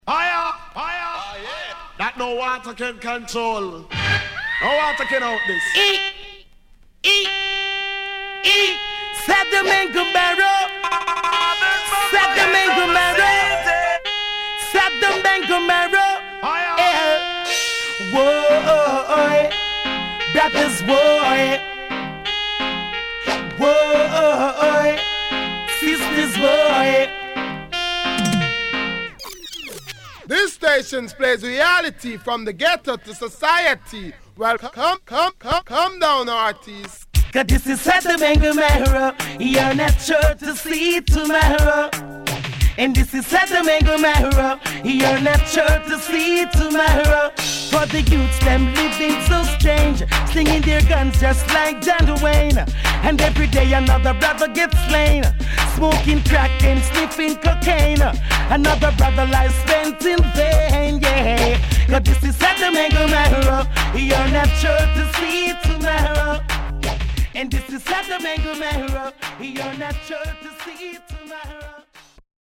HOME > Back Order [DANCEHALL DISCO45]
SIDE A:出だし少しノイズ入りますが良好です。